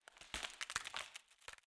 WAV · 143 KB · 立體聲 (2ch)